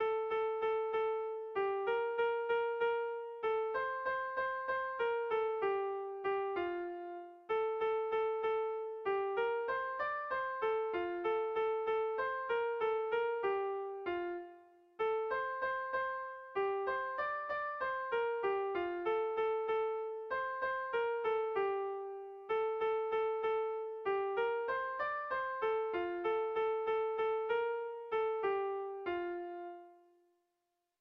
Bertso melodies - View details   To know more about this section
Kontakizunezkoa
A1A2BA2